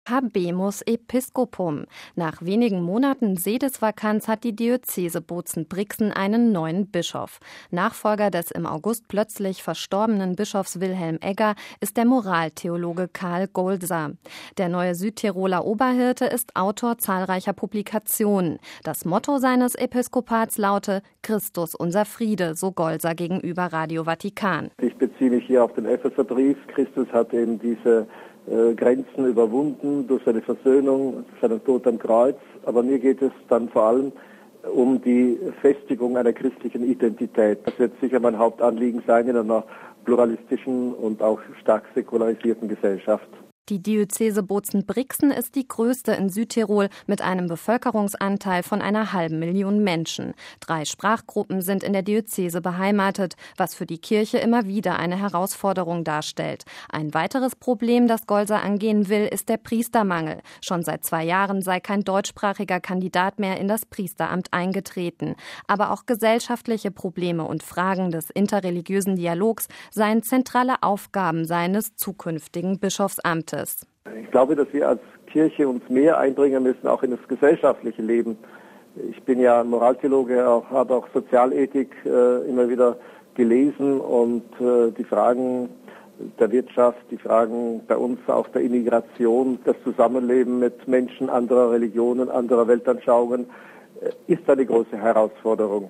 Das Motto seines Episkopats laute "Christus unser Friede", so Golser gegenüber Radio Vatikan: